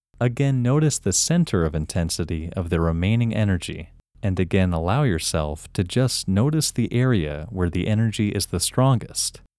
IN – First Way – English Male 14